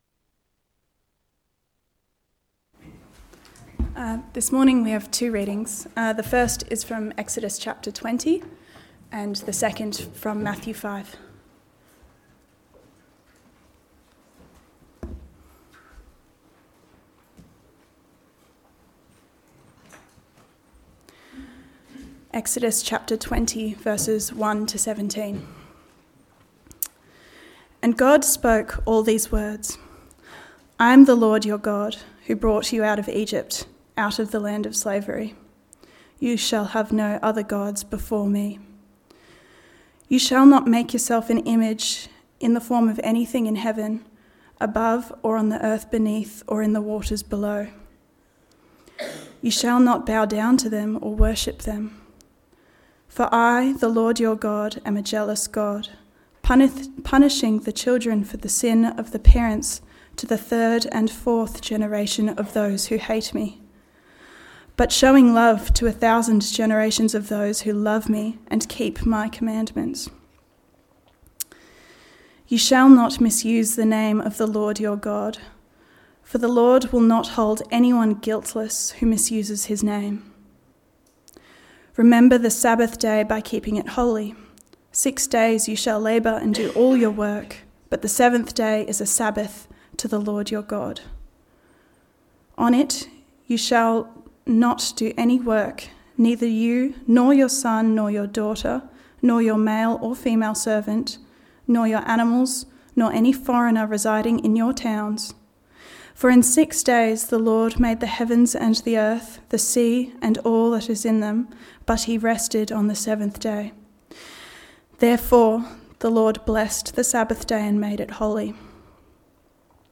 Text: Matthew 5: 17-20 Sermon